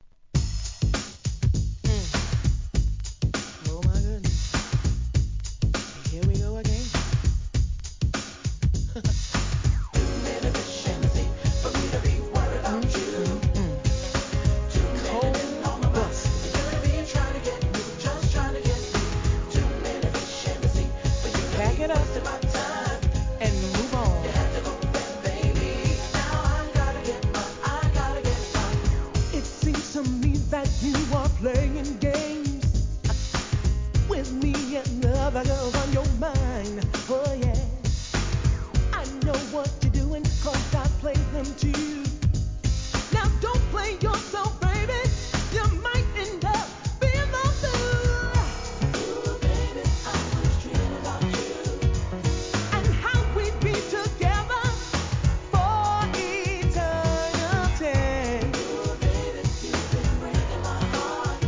HOUSE〜ソウルフルな内容!